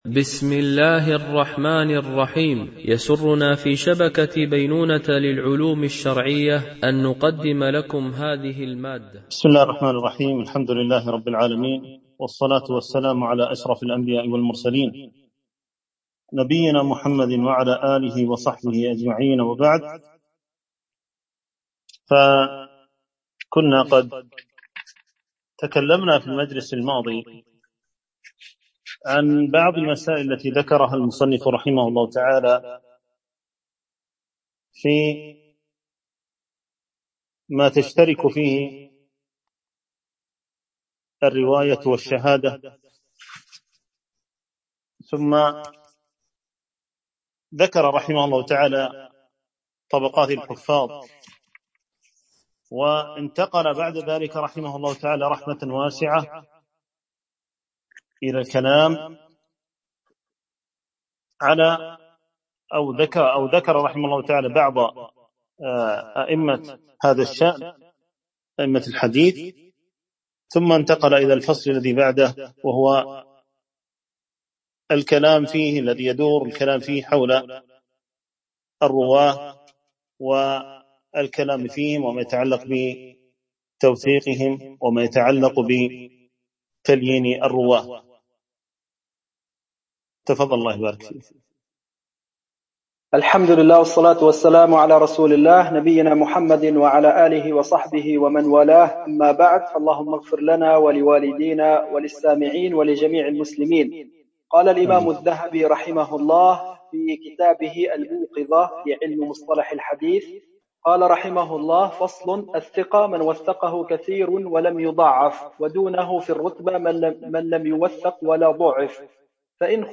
شرح الموقظة في علم مصطلح الحديث ـ الدرس 15 (الثقات ، ورجال الصحيحين )